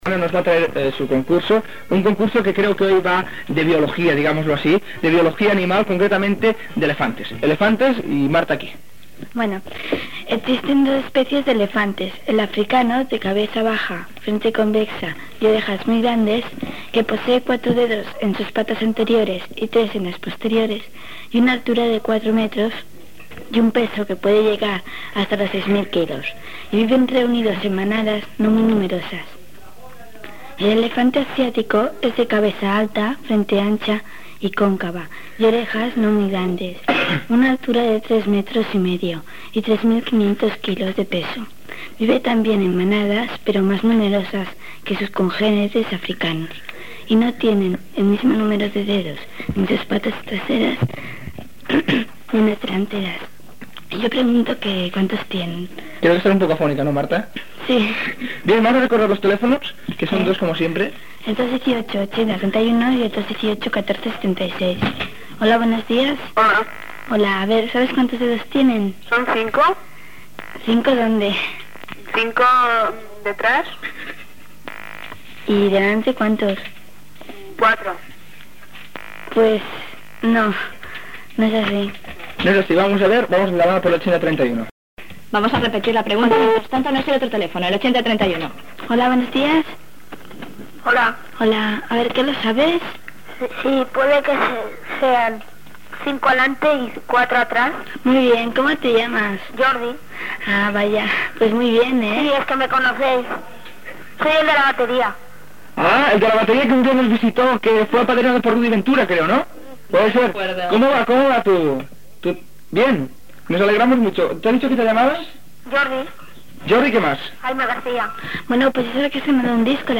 Dades sobre els elefants, pregunta i trucada dels oïdors
Infantil-juvenil